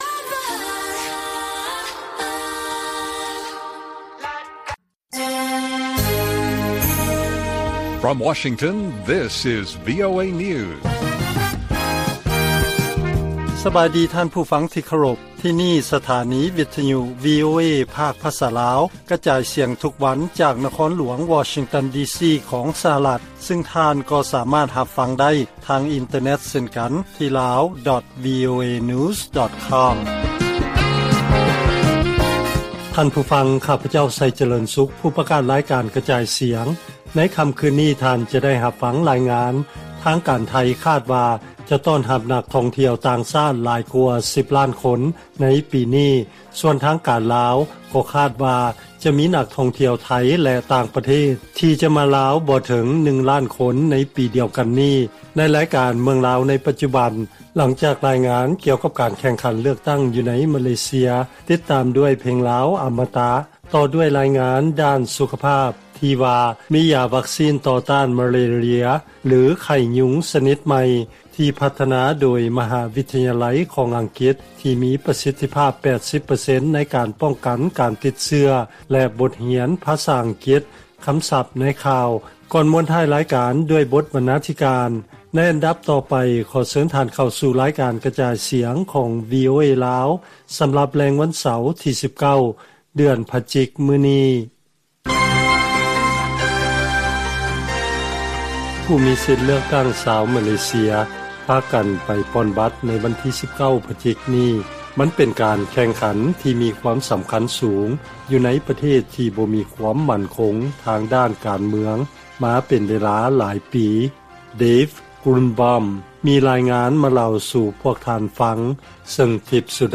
ລາຍການກະຈາຍສຽງຂອງວີໂອເອລາວ: ການໂຄສະນາຫາສຽງ ໃນການເລືອກຕັ້ງຂອງມາເລເຊຍ ດຳເນີນໄປຢ່າງຂຸ້ນຂ້ຽວ.